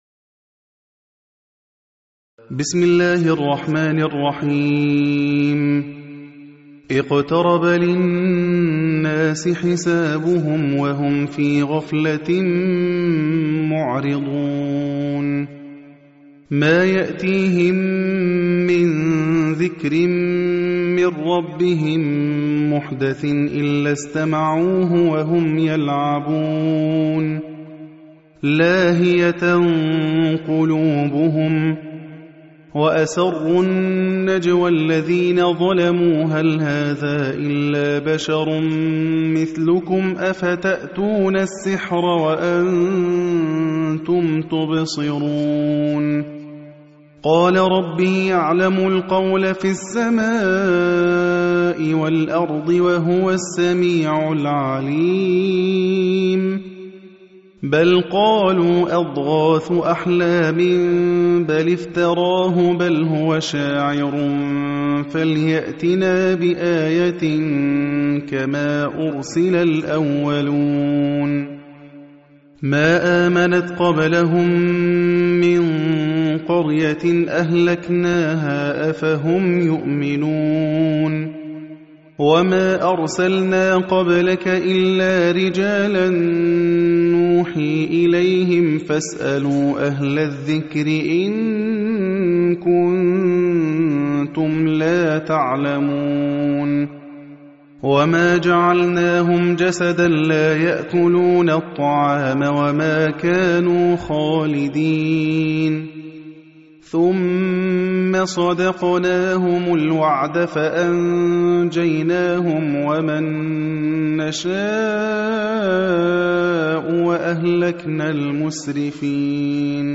Surah Sequence تتابع السورة Download Surah حمّل السورة Reciting Murattalah Audio for 21. Surah Al-Anbiy�' سورة الأنبياء N.B *Surah Includes Al-Basmalah Reciters Sequents تتابع التلاوات Reciters Repeats تكرار التلاوات